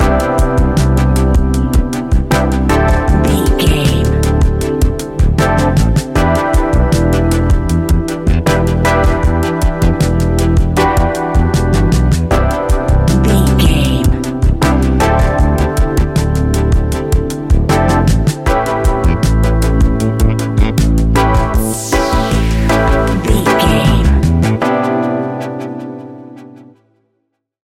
Ionian/Major
A♭
laid back
Lounge
sparse
new age
chilled electronica
ambient
atmospheric